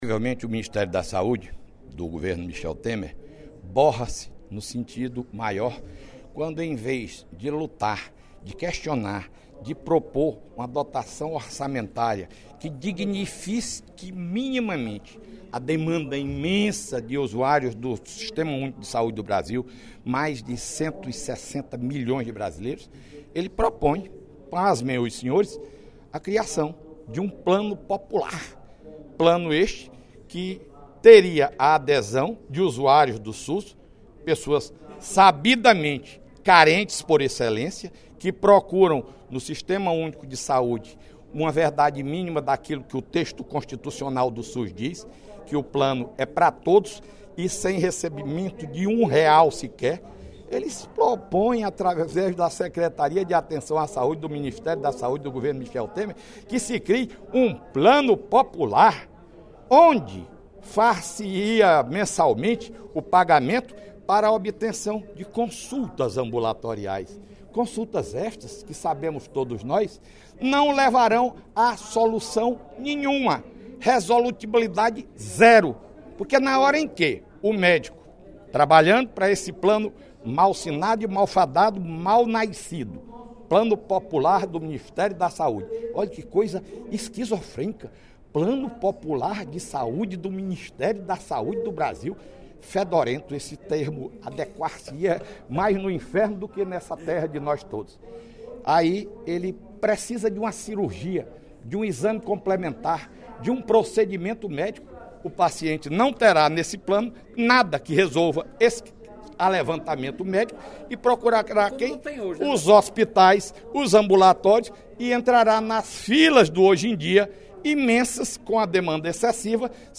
O deputado Fernando Hugo (PP) criticou, no primeiro expediente da sessão plenária desta sexta-feira (10/03), a proposta do Ministério da Saúde para a criação do Plano Popular de Saúde.